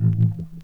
Bass_Stab_04.wav